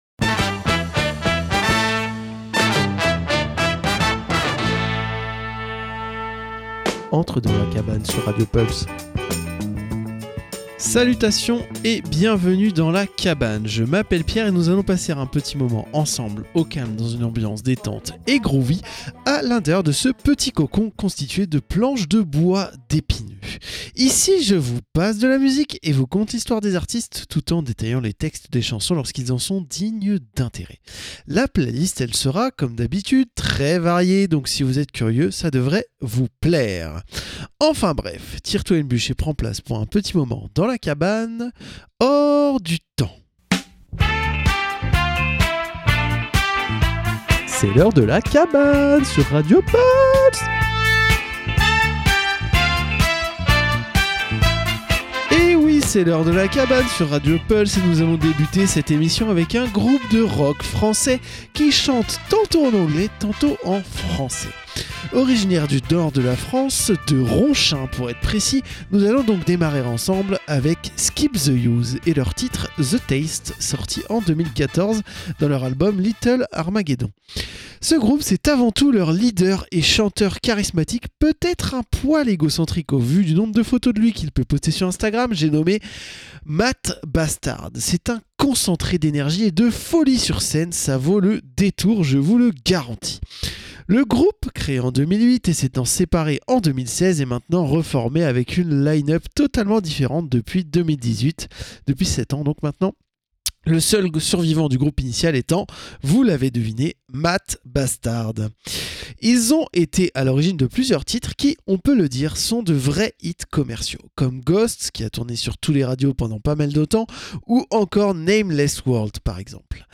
La cabane, détente dans une ambiance chill/groovy avec une playlist éclectique allant du rock à la funk en passant par la pop, le rap ou l'électro. Au programme : écoute et découverte ou redécouverte d'artistes et explication des paroles de grands classiques de la musique !